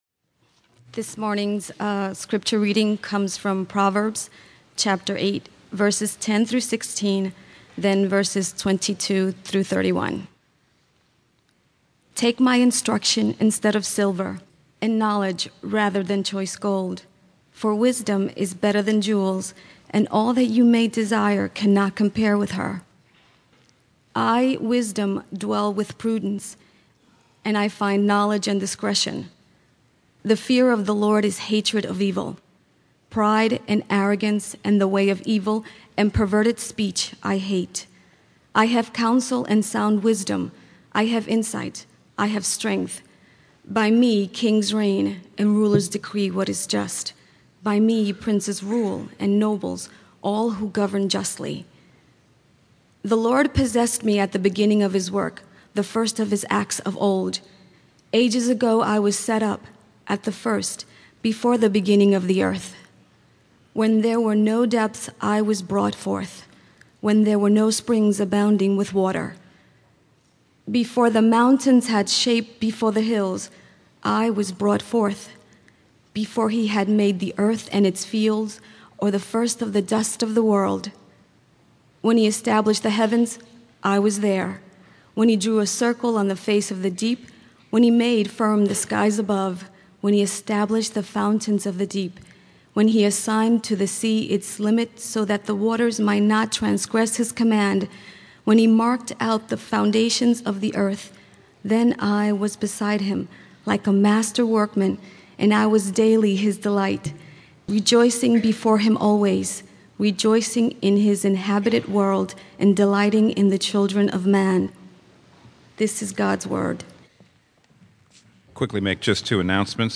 Sermons | Local Church